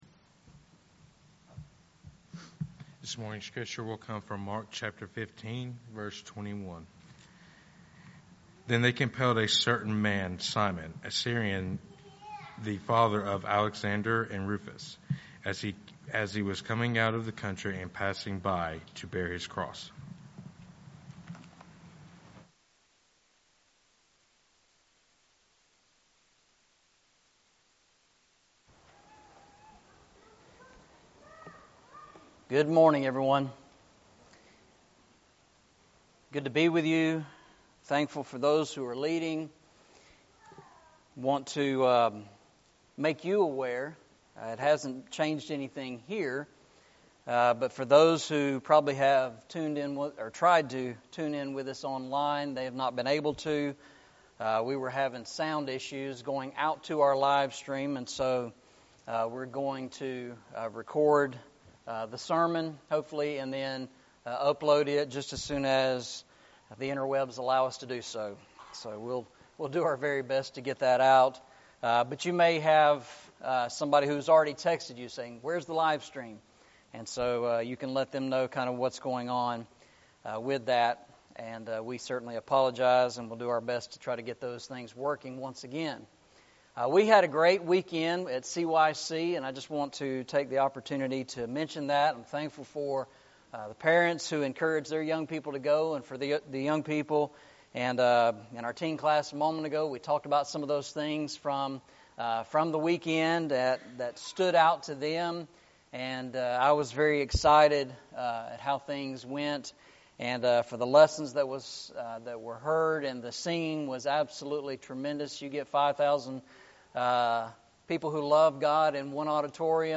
Series: Eastside Sermons Passage: Mark 15:21 Service Type: Sunday Morning « Is the Kingdom the Same as the Church?